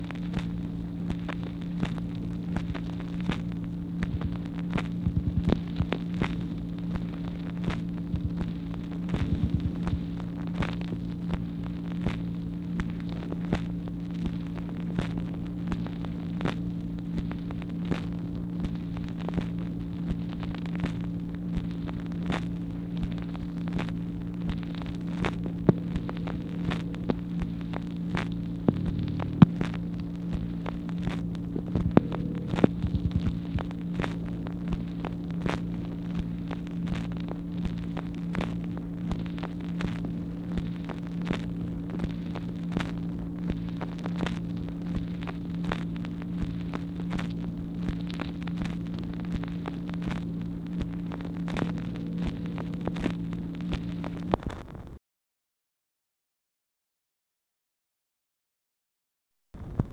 MACHINE NOISE, May 11, 1964
Secret White House Tapes | Lyndon B. Johnson Presidency